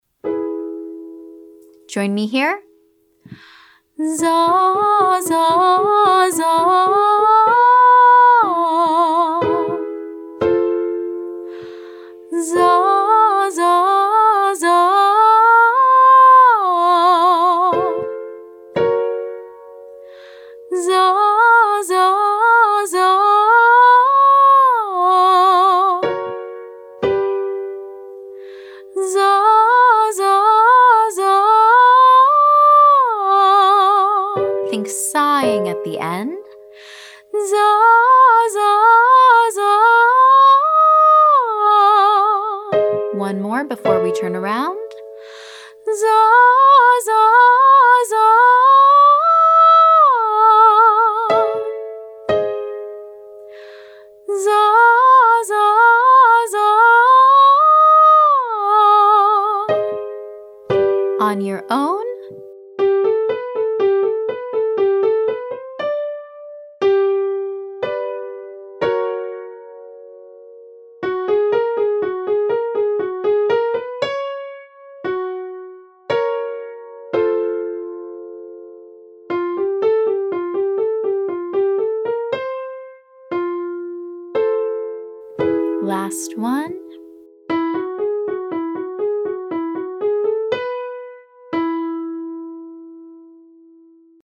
I'm singing a nice long phrase that moves up and down and then at the end practicing just letting go of my voice and allowing it to completely decompress.